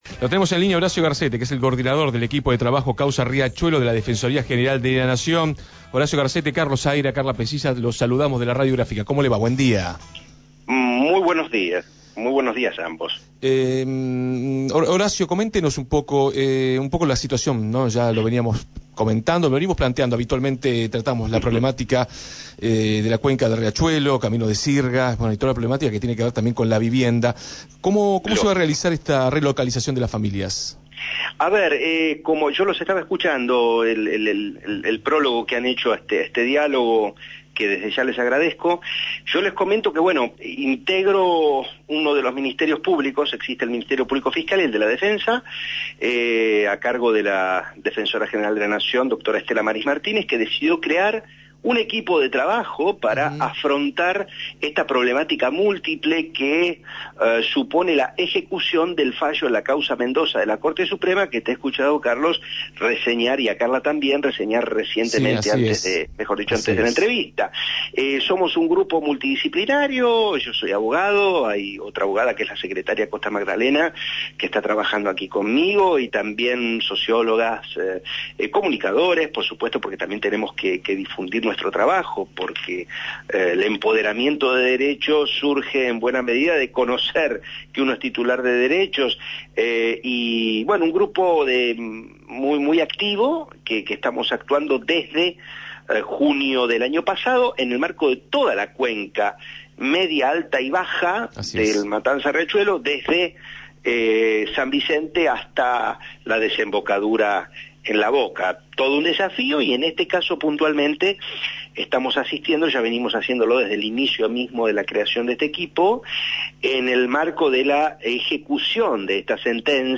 entrevistaron